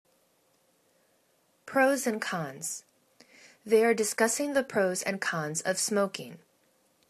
pros and cons      /pro:z ənd conz/    phrase